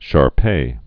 (shärpā)